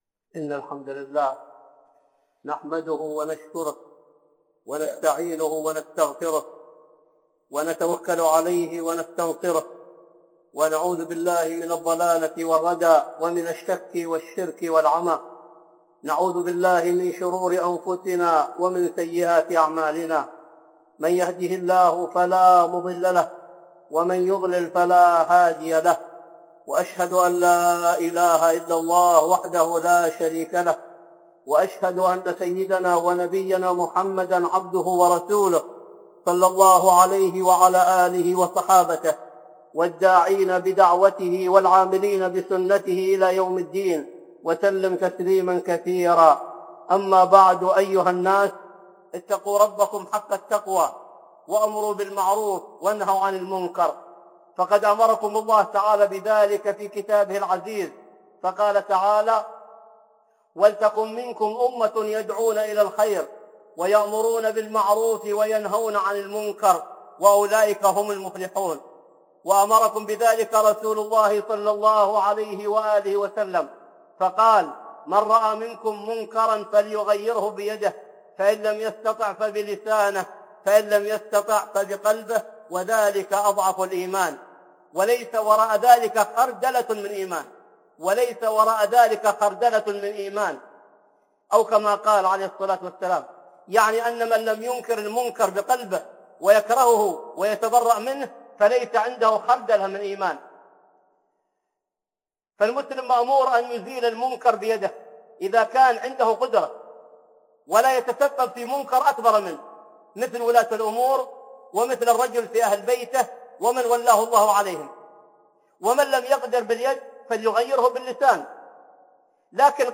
خطبة جمعة) الأمر بالمعروف والنهي عن المنكر